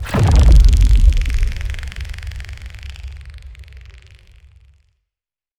net_blowout.ogg